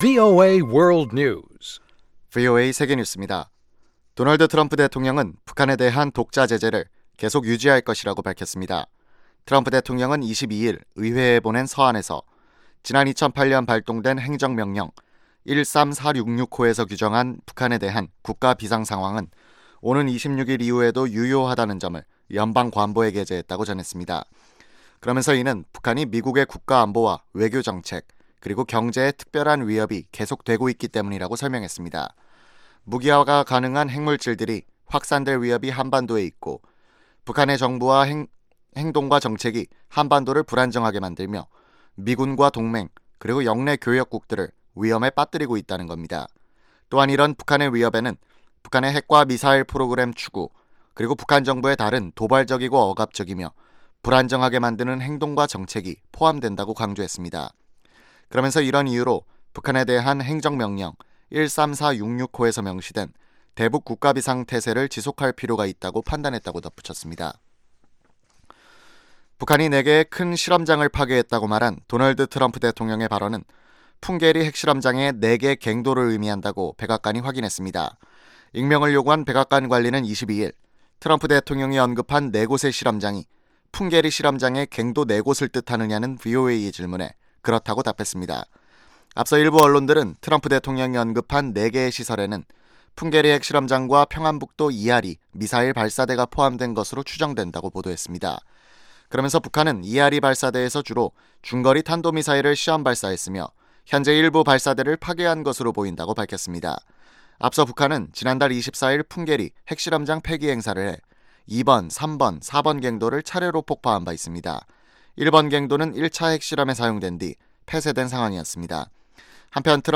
VOA 한국어 아침 뉴스 프로그램 '워싱턴 뉴스 광장' 2018년 6월 23일 방송입니다. 도널드 트럼프 대통령은 북한이 완전한 비핵화를 조속히 시작하는데 합의했다며 이 과정이 이미 진행 중이라고 밝혔습니다. 북한의 심각한 인권문제에 대한 논의를 외면하면 안보 문제에 대한 합의가 부정적인 영향을 받을 것이라고 유엔 북한인권 특별보고관이 말했습니다.